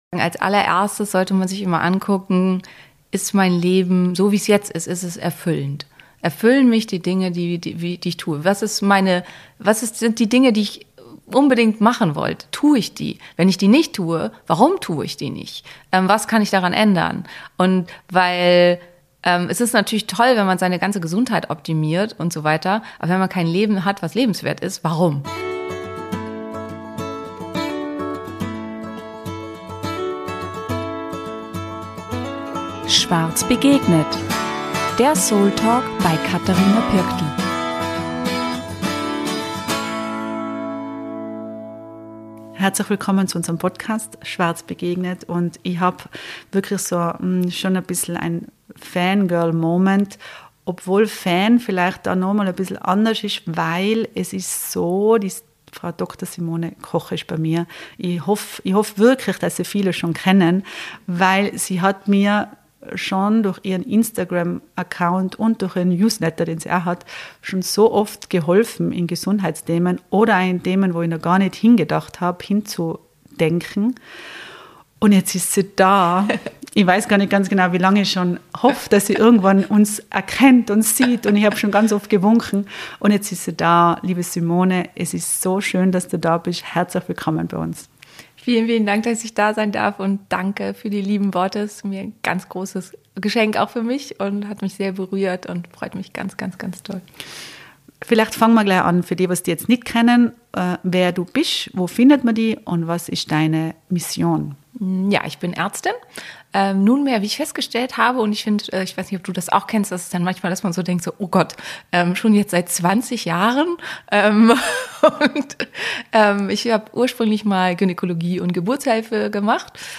Mit ihrer klaren und sympathischen Art erklärt sie komplexe Zusammenhänge verständlich und alltagstauglich.